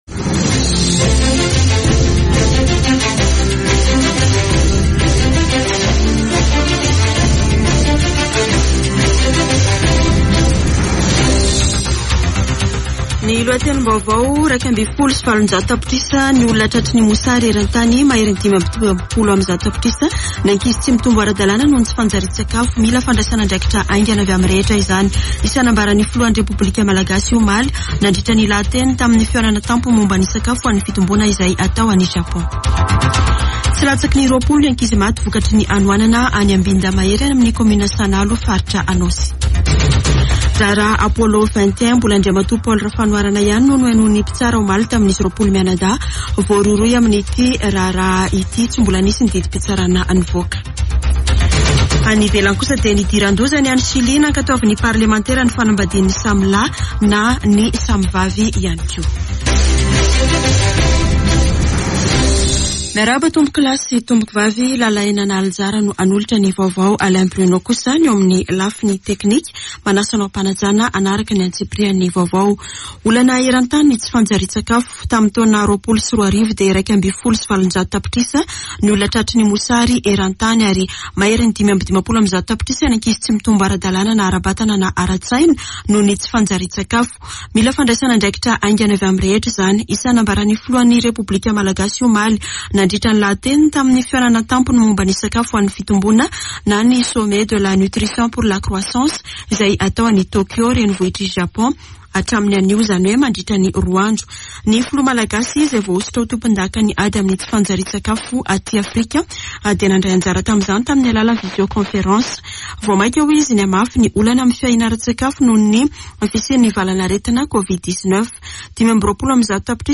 [Vaovao maraina] Alarobia 08 desambra 2021